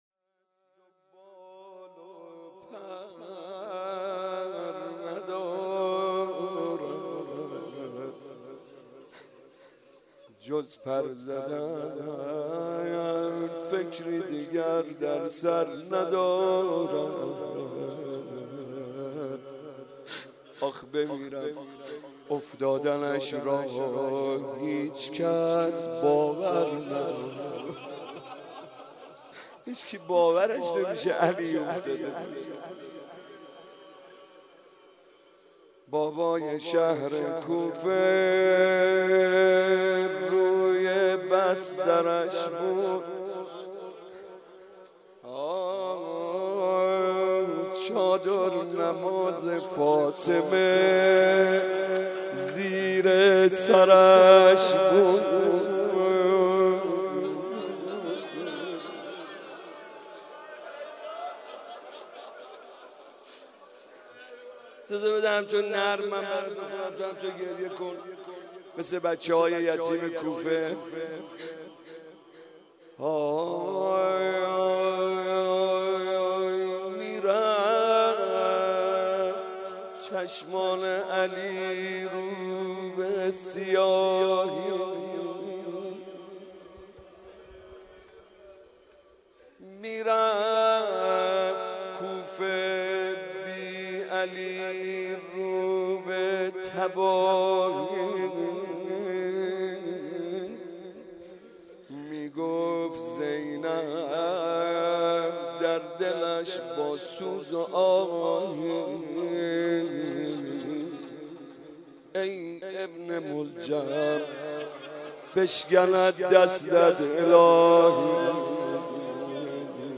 مناجات
مناسبت : شب بیست و یکم رمضان - شب قدر دوم